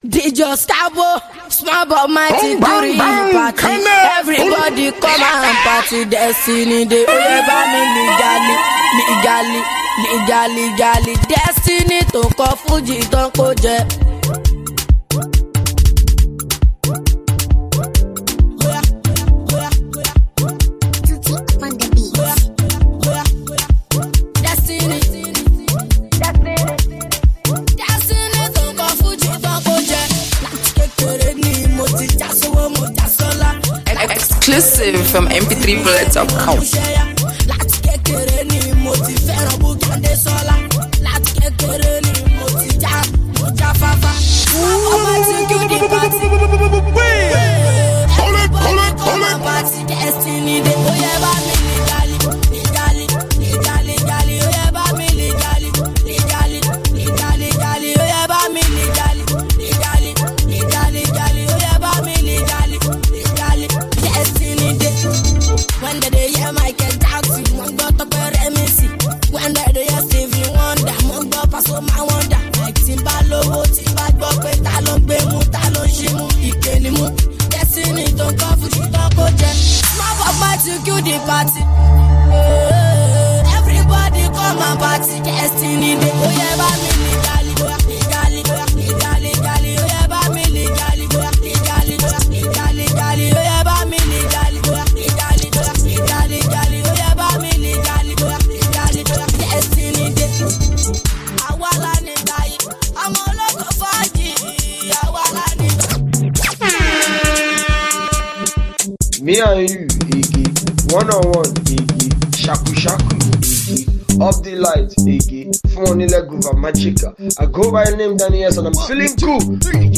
mixtape